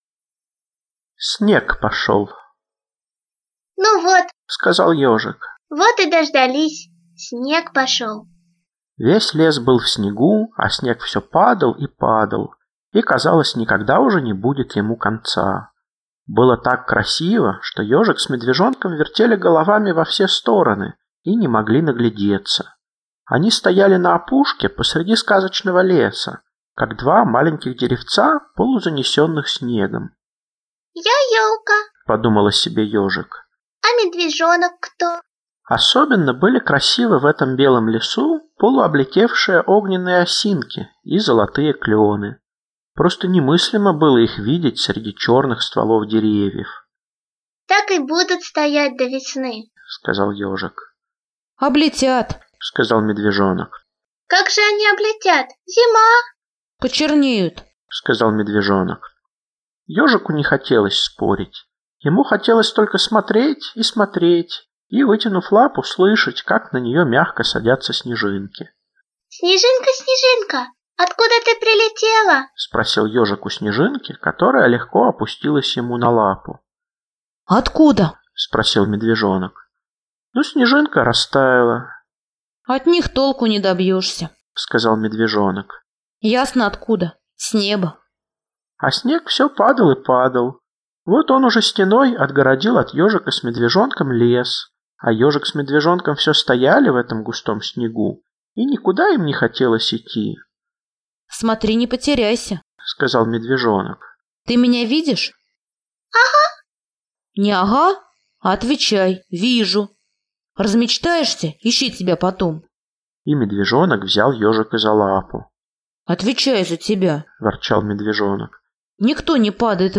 Ёжик и Медвежонок – Козлов С.Г. (аудиоверсия)